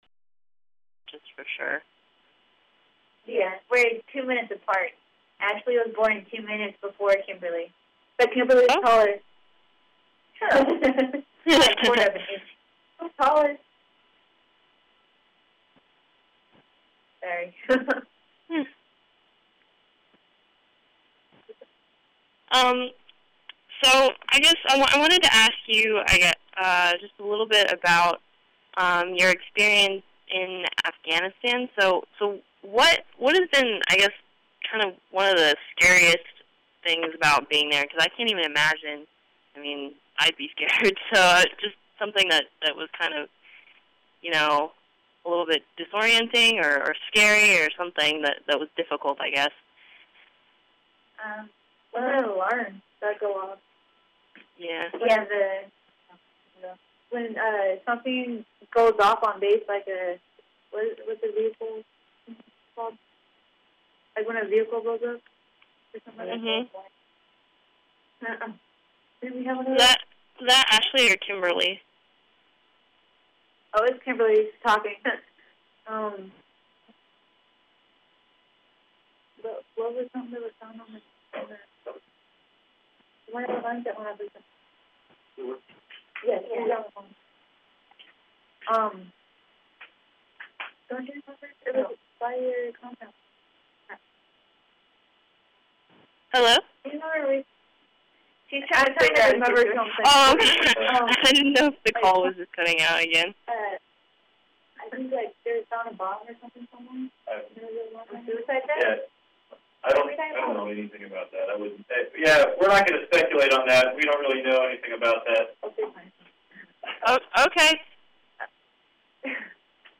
talk to a Bakersfield Californian reporter about their current deployment to Afghanistan and much more.